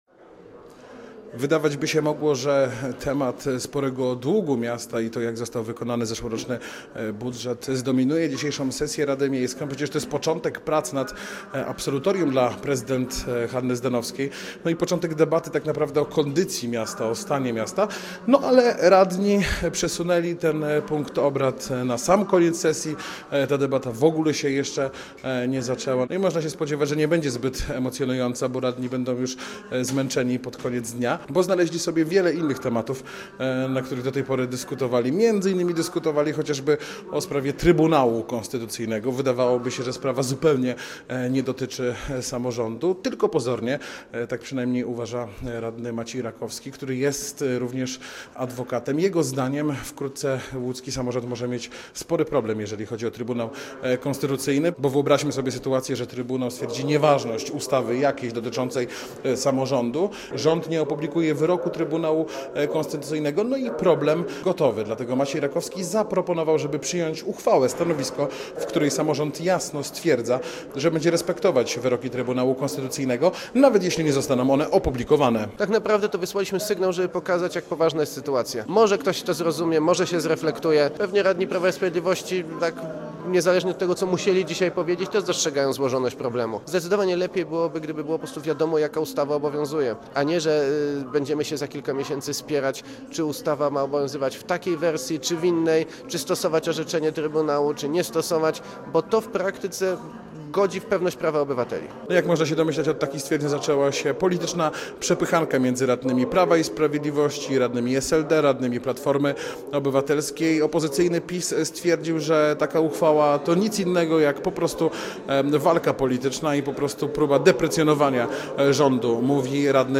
Posłuchaj i dowiedz się więcej: Nazwa Plik Autor Relacja z sesji audio (m4a) audio (oga) ZDJĘCIA, NAGRANIA WIDEO, WIĘCEJ INFORMACJI Z ŁODZI I REGIONU ZNAJDZIESZ W DZIALE “WIADOMOŚCI”.